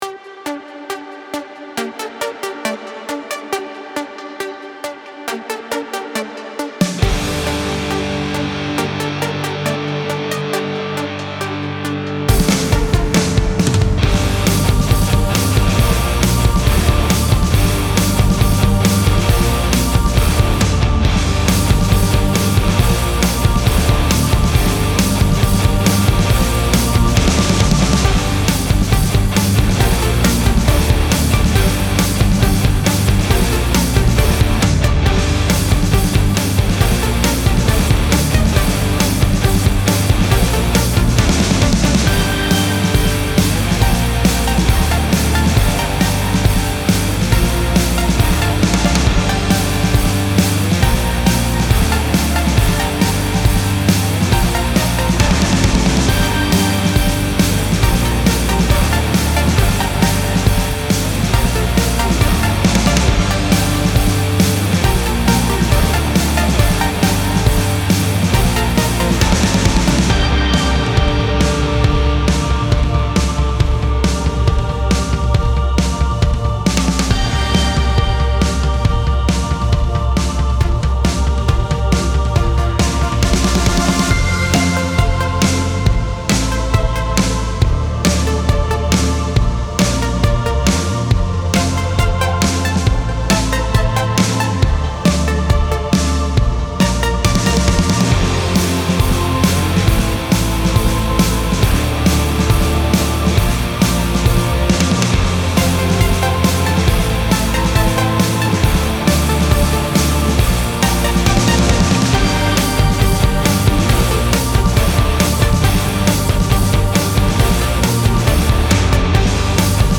Style Style EDM/Electronic, Orchestral, Rock +1 more
Mood Mood Bright, Driving, Epic +2 more
Featured Featured Bass, Choir, Drums +3 more
BPM BPM 137